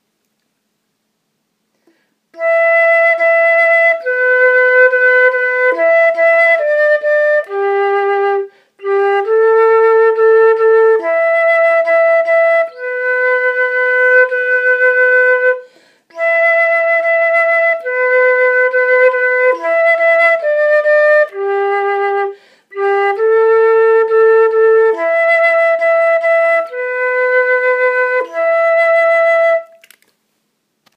Chants
qui-regarde-vers-lui-basse.m4a